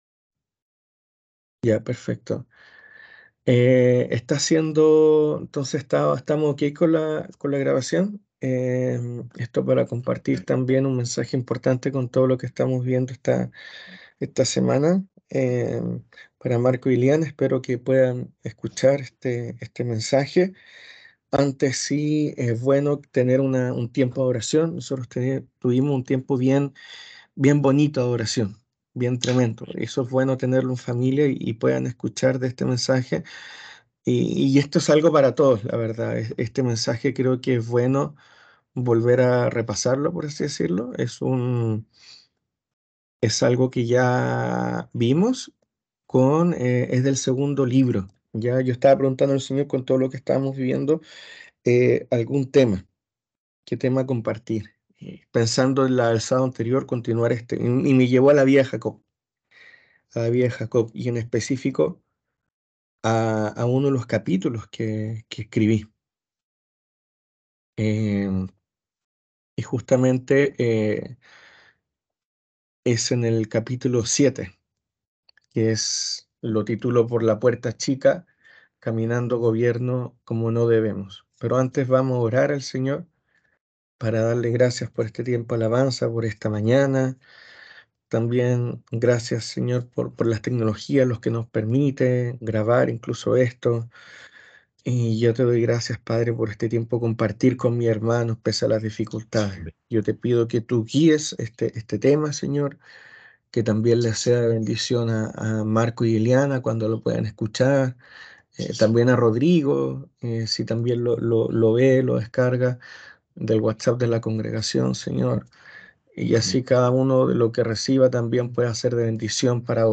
Esta es una prédica que realicé el sábado 2 de diciembre de este 2023, que el Señor me llevó a grabar, lo que comúnmente no realizo.